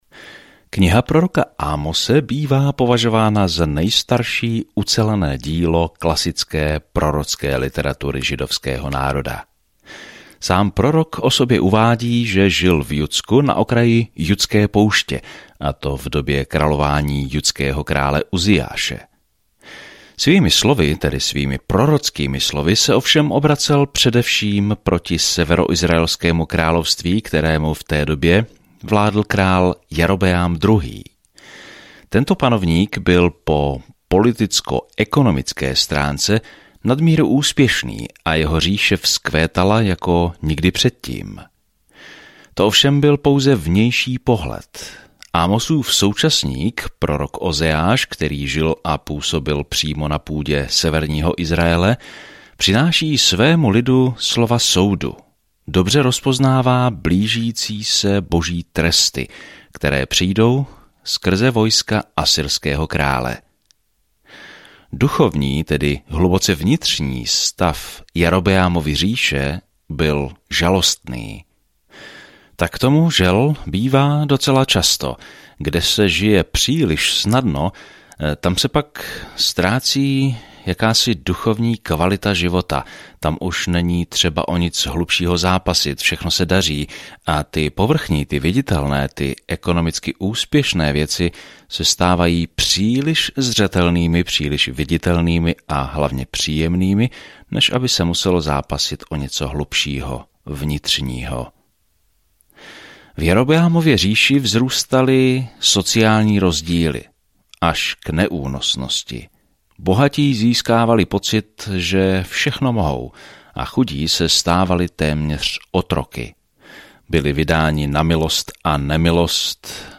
Písmo Amos 1:1 Začít tento plán Den 2 O tomto plánu Amos, venkovský kazatel, jde do velkého města a odsuzuje jejich hříšné způsoby a říká, že všichni jsme zodpovědní Bohu podle světla, které nám dal. Denně procházejte Amosem a poslouchejte audiostudii a čtěte vybrané verše z Božího slova.